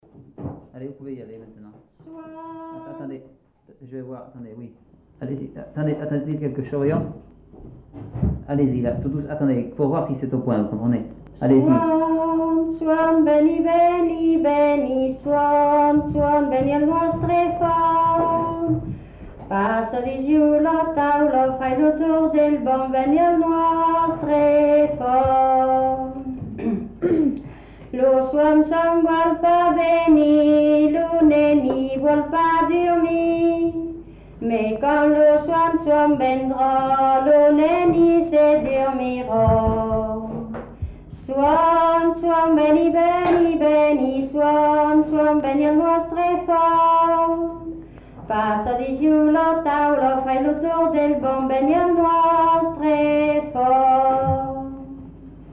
enquêtes sonores